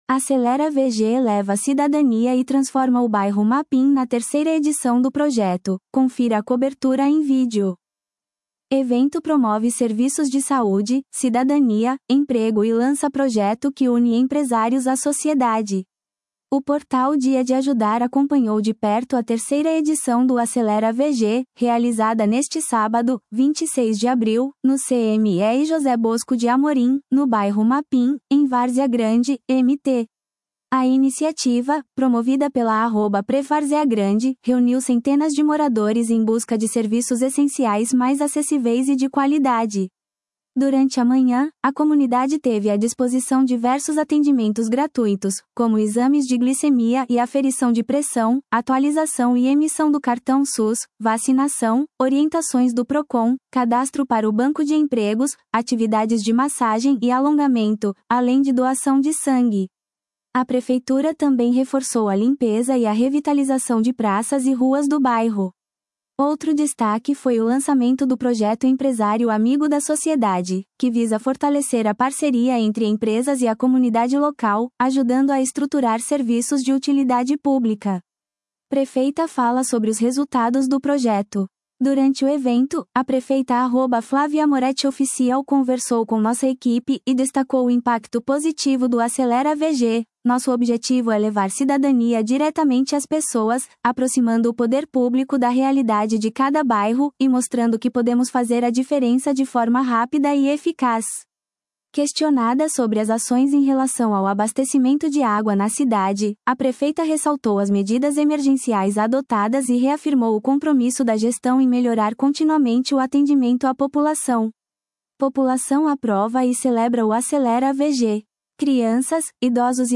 Acelera VG leva cidadania e transforma o Bairro Mapim na 3ª edição do projeto; confira a cobertura em vídeo
Crianças, idosos e adultos também deram seus depoimentos à nossa equipe, ressaltando a importância de ter serviços de saúde, documentação e assistência social tão perto de casa.